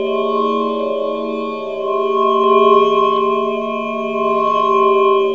A#3 NEURO04L.wav